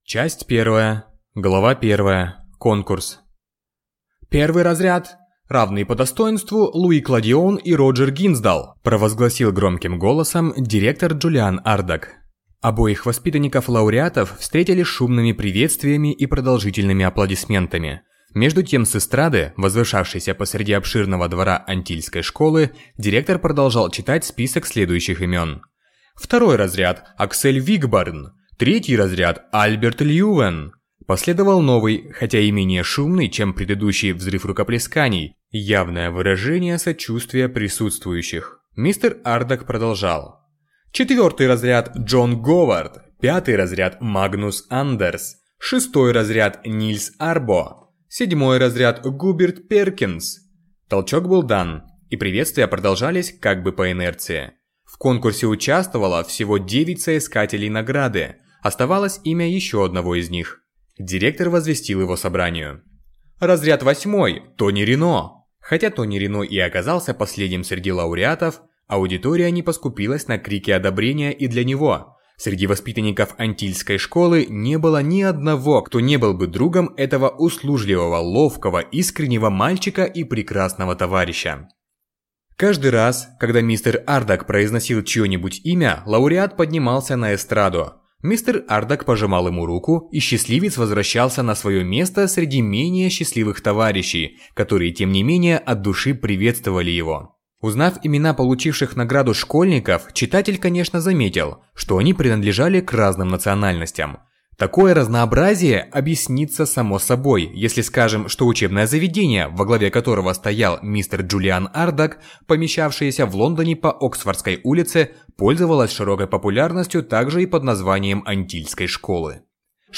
Аудиокнига Юные путешественники | Библиотека аудиокниг